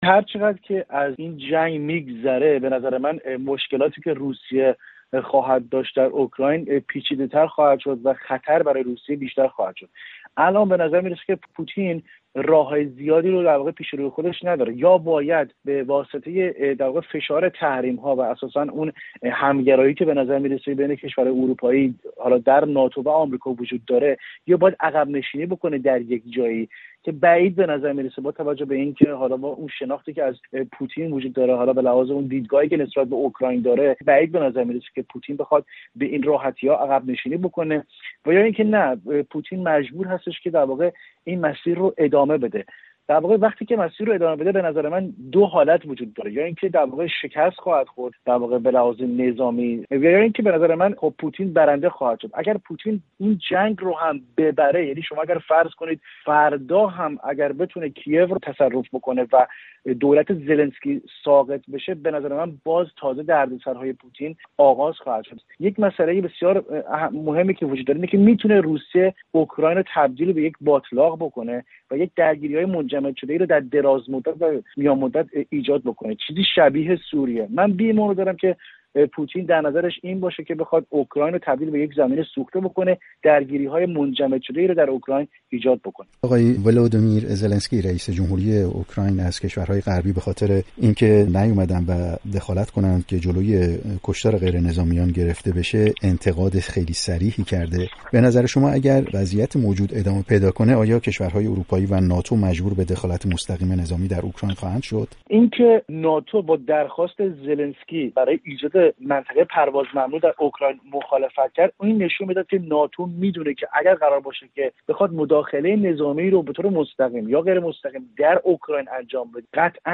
برنامه‌های رادیویی